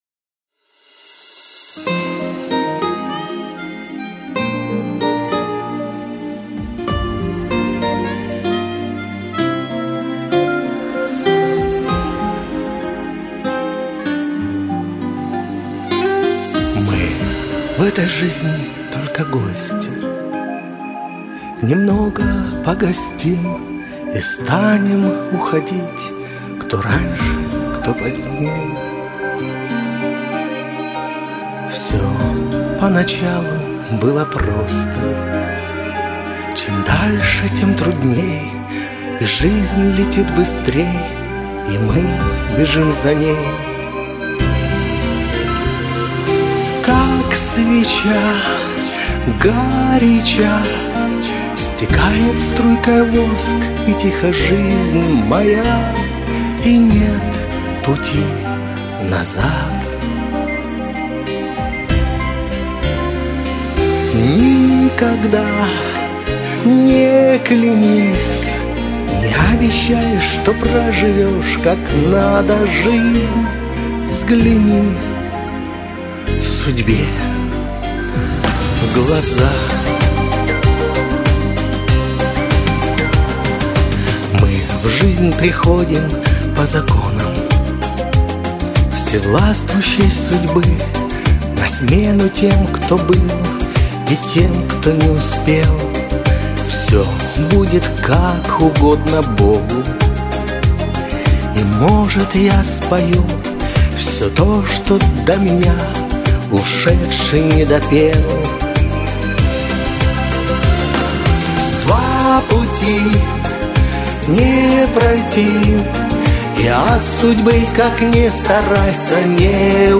Тенор